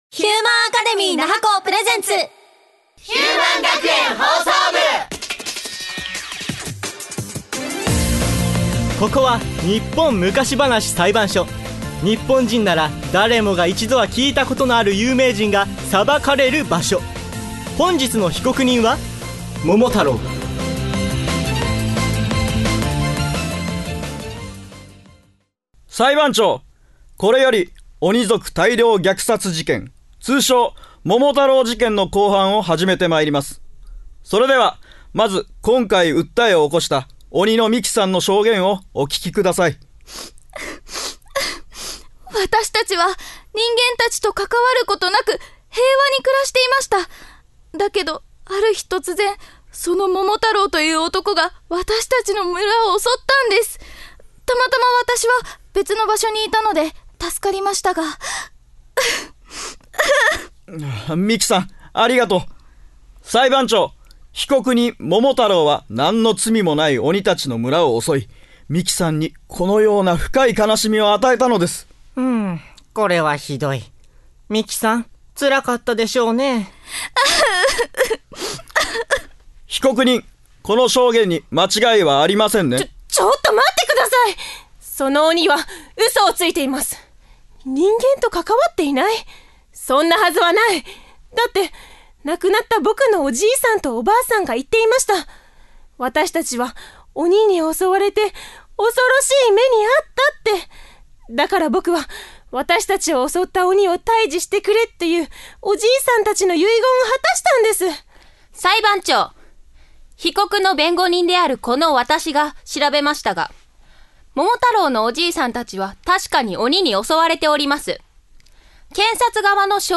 150119ヒューマン学園ラジオドラマ「日本昔話裁判所」/ヒューマンアカデミー那覇校声優専攻7期